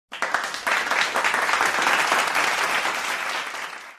Sound Effects
Clap 1